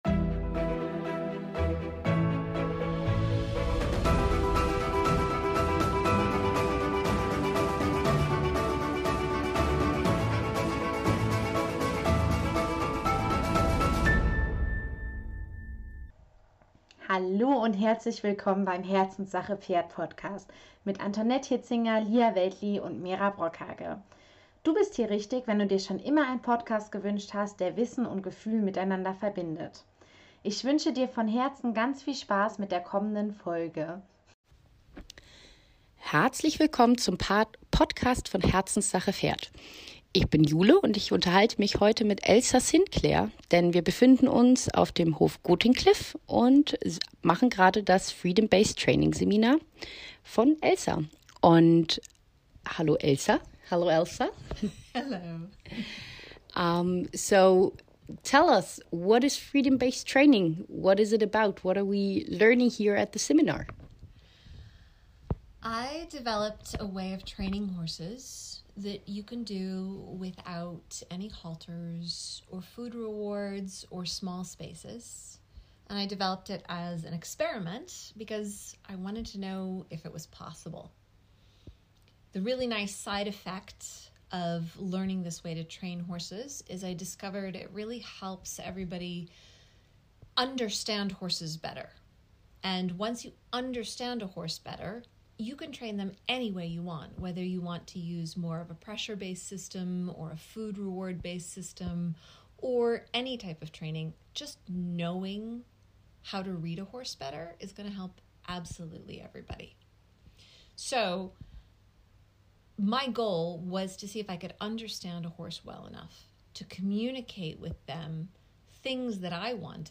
gut verständliches Englisch) ~ Pferde-Wissen mit Gefühl - Der Podcast von Herzenssache Pferd Podcast